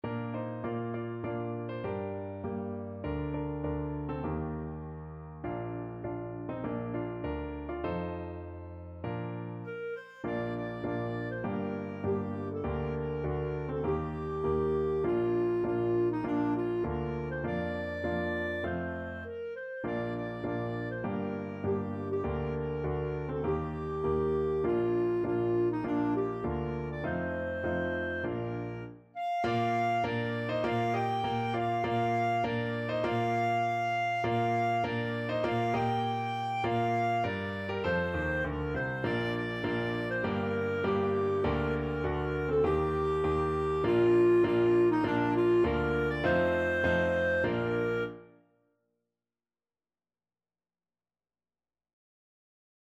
4/4 (View more 4/4 Music)
Moderato
Traditional (View more Traditional Clarinet Music)